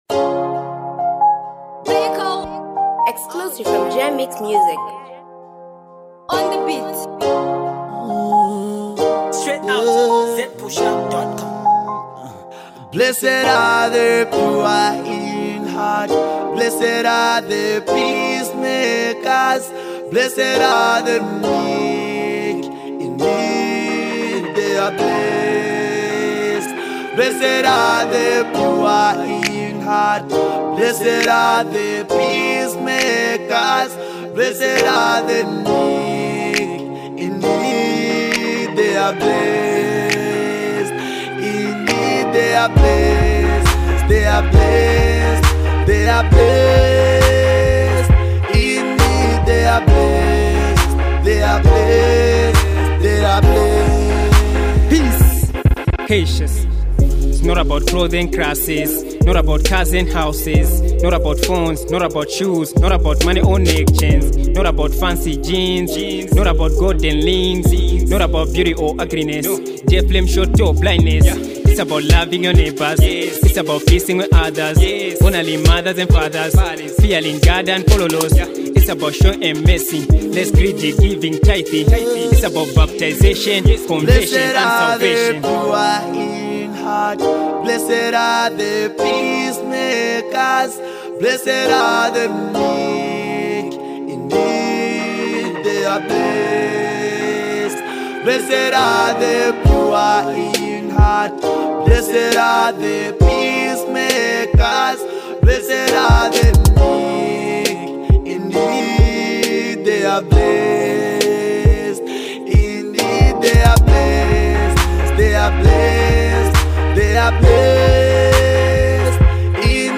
something motivational